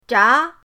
zha2.mp3